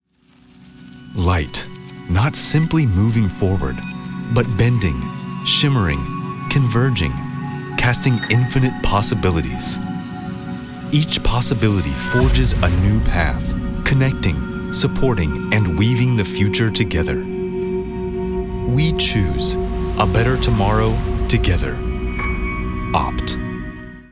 英語ネイティヴナレーターキャスティング
今回は、男性のアメリカ英語ネイティヴナレーターとのことで、